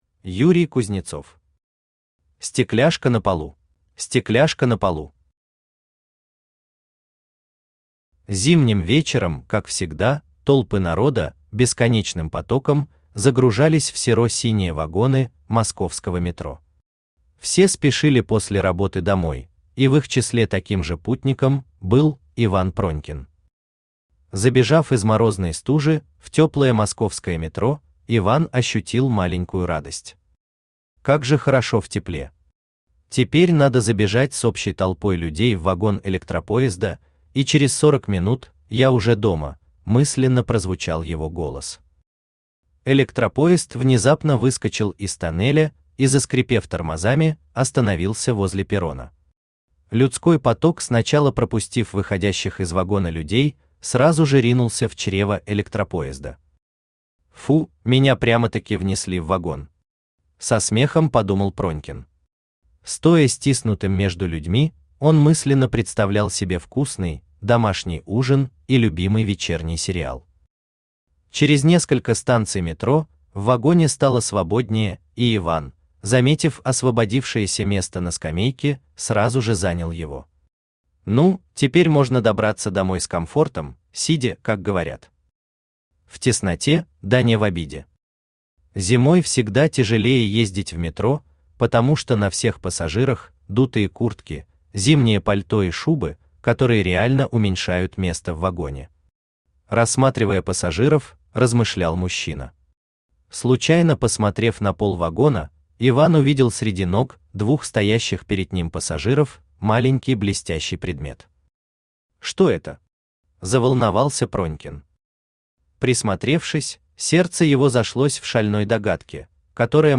Аудиокнига Стекляшка на полу | Библиотека аудиокниг
Aудиокнига Стекляшка на полу Автор Юрий Юрьевич Кузнецов Читает аудиокнигу Авточтец ЛитРес.